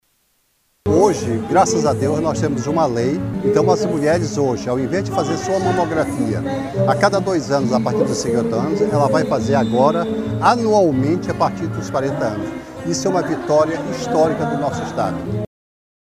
O diretor-presidente da Fundação Centro de Controle de Oncologia do Estado do Amazonas (FCecon), Gerson Mourão, destaca a importância do movimento e o avanço na prevenção da doença com a Lei.